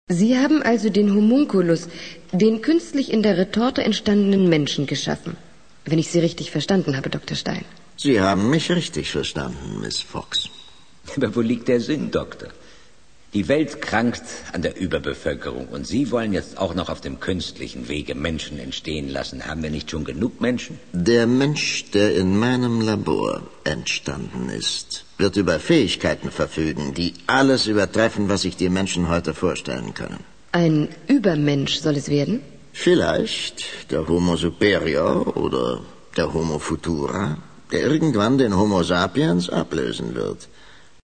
Was ihr hier zu sehen bekommt, ist echter Hörspiel-Kult.